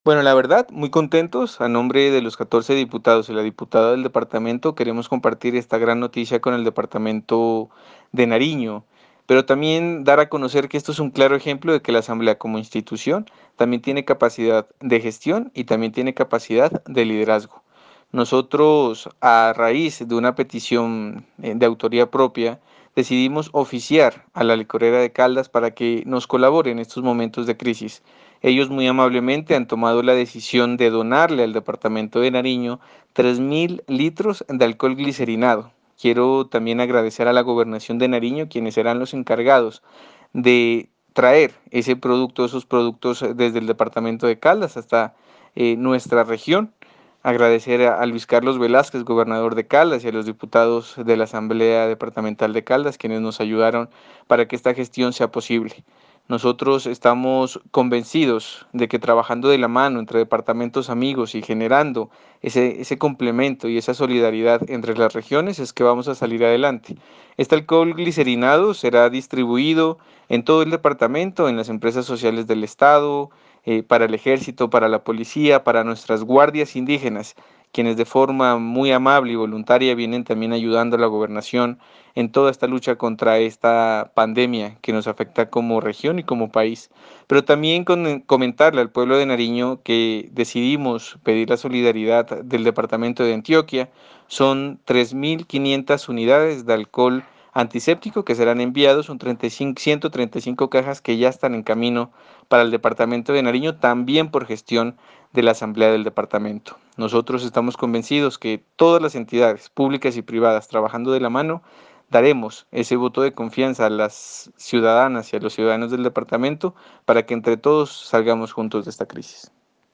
Declaración del diputado Jesús Eraso:
Audio-Diputado-Jesús-Eraso.ogg